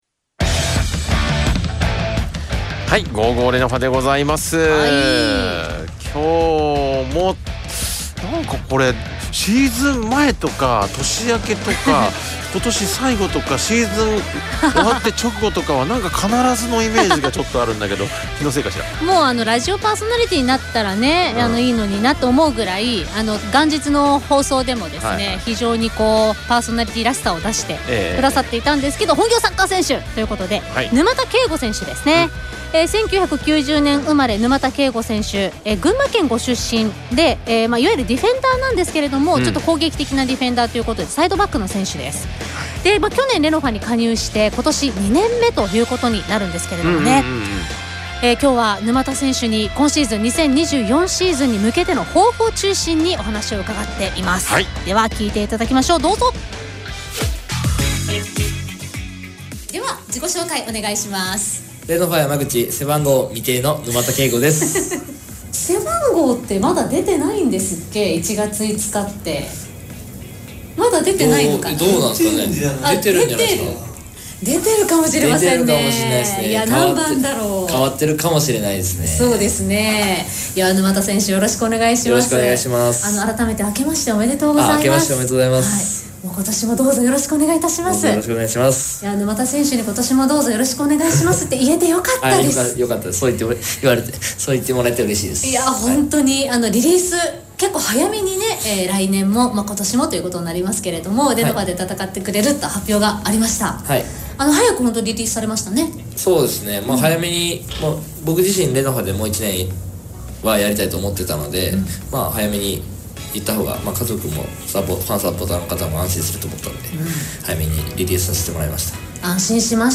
リポーター